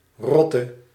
The Rotte (Dutch pronunciation: [ˈrɔtə]